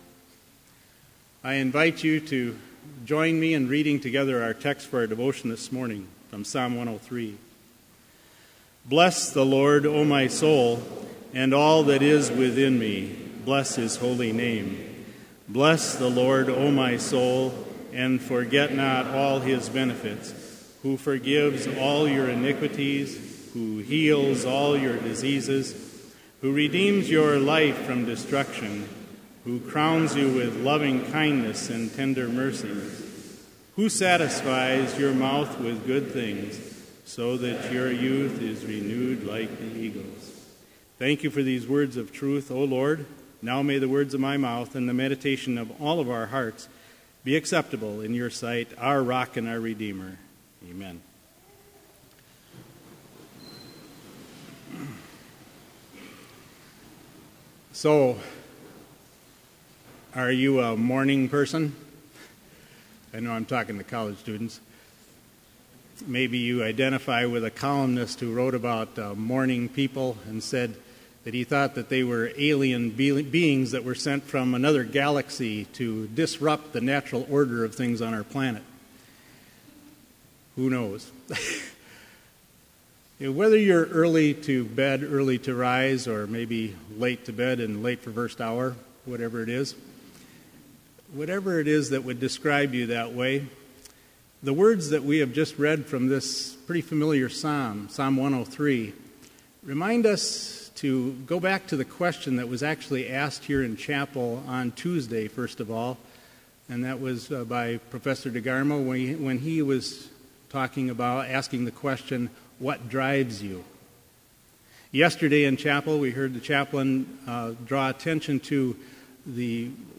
Complete service audio for Chapel - September 10, 2015